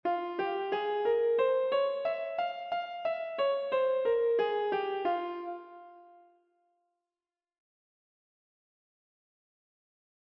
＜Ｆハーモニックマイナースケール＞